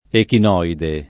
[ ekin 0 ide ]